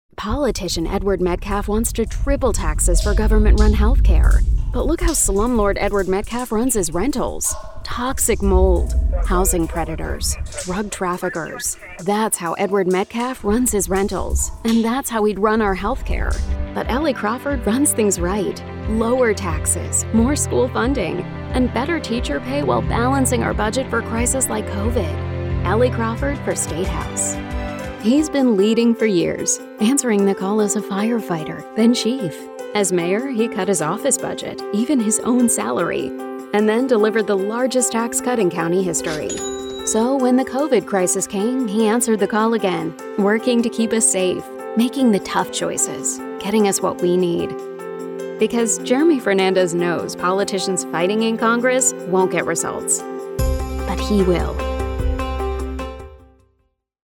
Female Republican Voices
Variety of great voice actors with pro home studios and Source Connect.